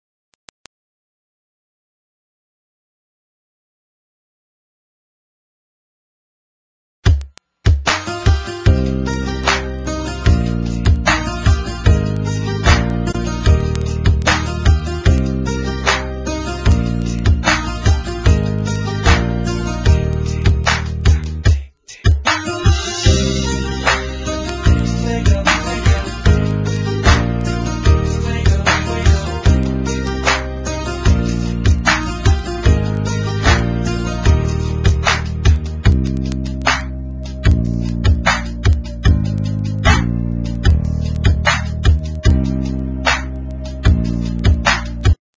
NOTE: Vocal Tracks 1 Thru 8